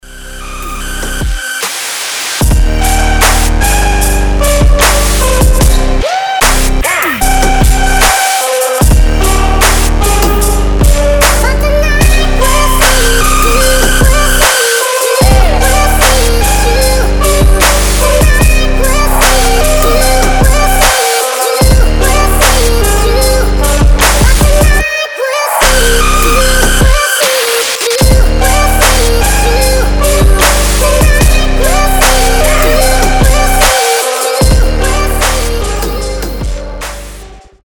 • Качество: 320, Stereo
жесткие
мощные
атмосферные
басы
Атмосферный трап с забавным голосом